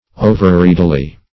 Meaning of overreadily. overreadily synonyms, pronunciation, spelling and more from Free Dictionary.
-- O"ver*read"*i*ly , adv.